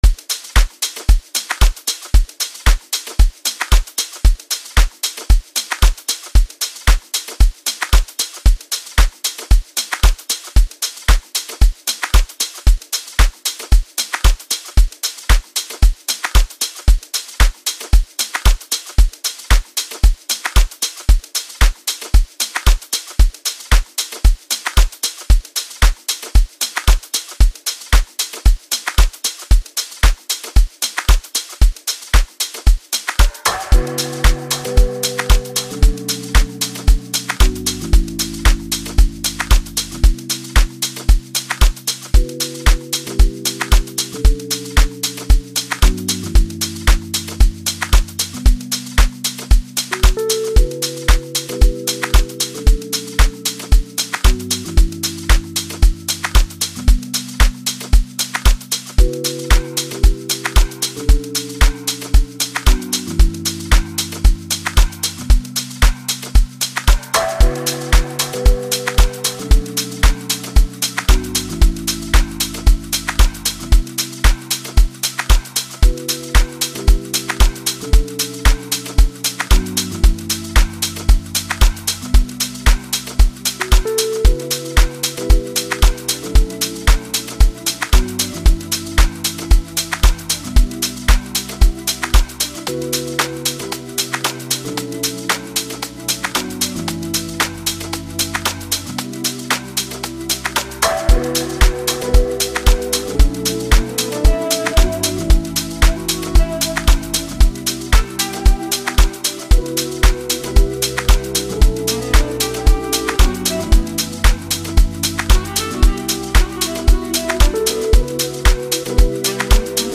captivating and soulful tune
Amapiano